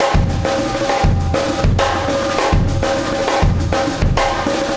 Extra Terrestrial Beat 22.wav